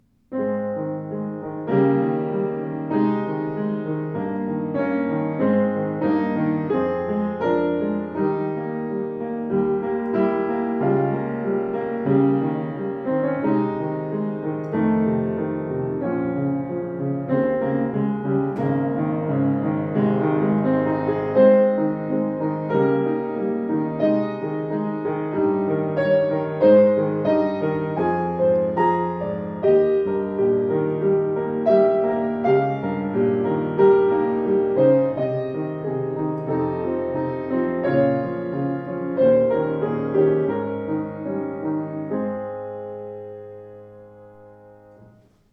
Klavier Yamaha YU10 schwarz
Schwarz poliert mit ausdruckstarkem Klang und klaren Bässen (121cm Bauhöhe wie das bekannte Modell Yamaha U1)